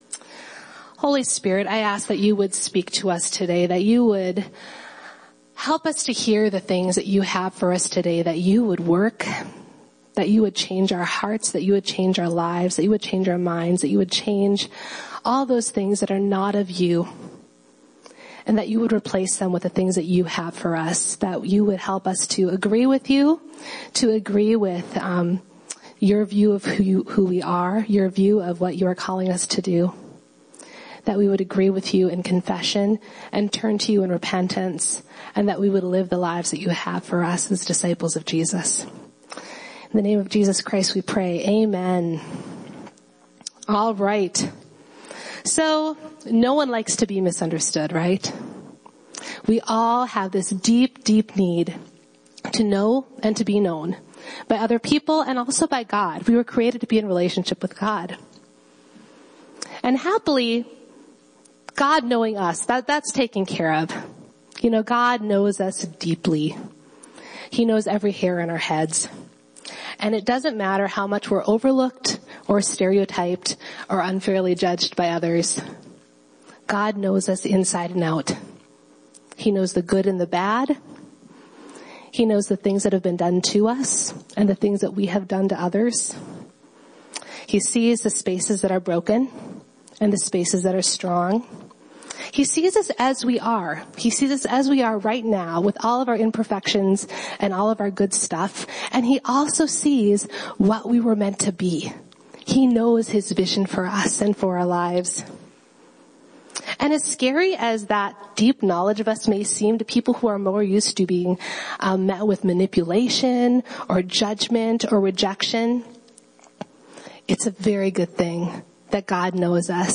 Listen to the full audio sermon here